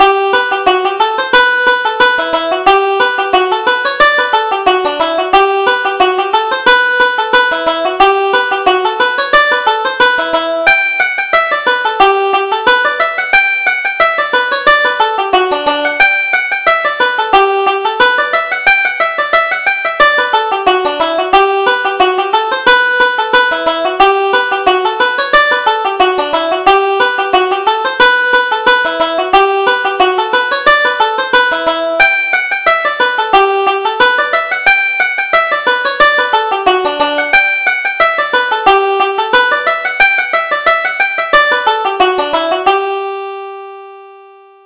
Reel: The Rambler in Cork